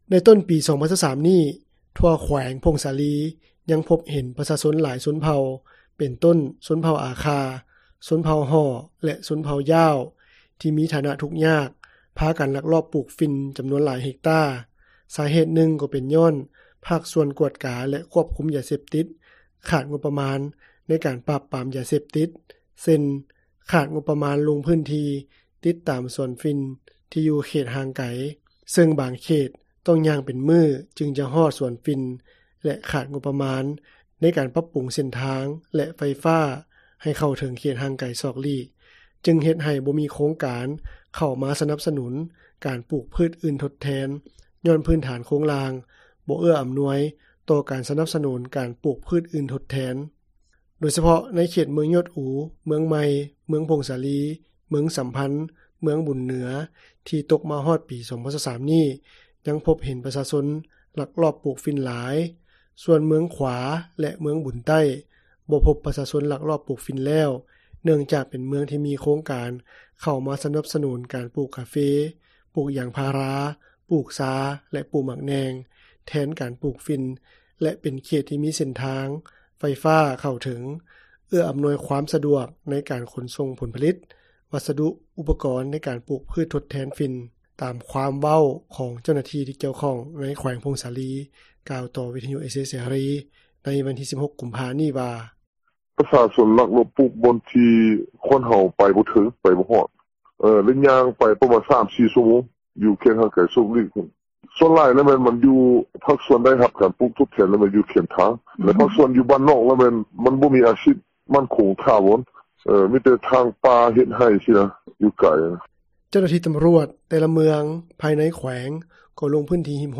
ນັກຂ່າວ ພົລເມືອງ
ຕາມຄວາມເວົ້າ ຂອງເຈົ້າໜ້າທີ່ ທີ່ກ່ຽວຂ້ອງ ໃນແຂວງຜົ້ງສາລີ ກ່າວຕໍ່ວິທຍຸເອເຊັຽເສຣີ ໃນວັນທີ 16 ກຸມພາ ນີ້ວ່າ: